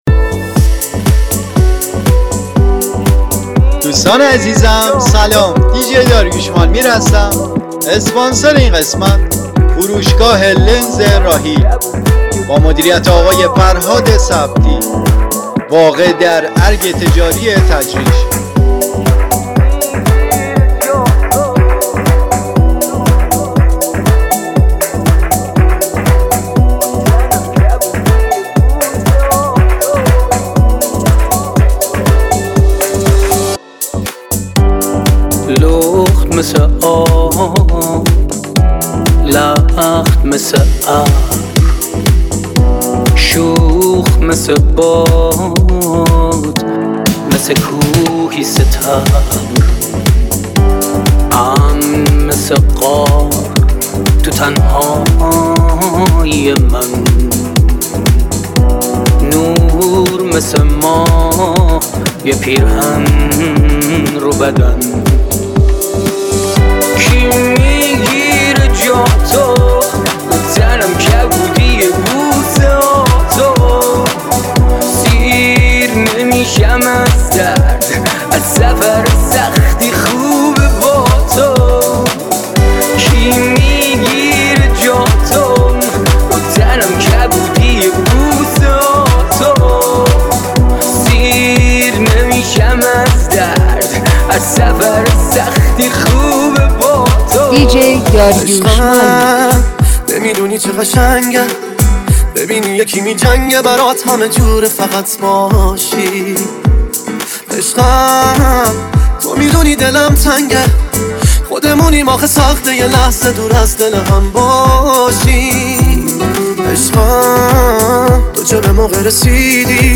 انفجار انرژی
یه پادکست فوق‌العاده و پرانرژی برای شما آماده کردیم!